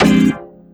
50SYNT01  -L.wav